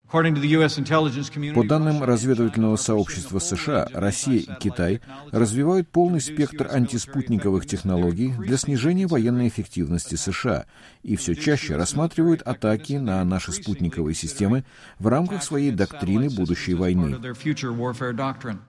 Вице-президент США Майк Пенс выступает на фоне шаттла на заседании Национального космического совета.
Пенс выступил в четверг на первом заседании Национального космического совета, воссозданного с момента его расформирования в 1993 году.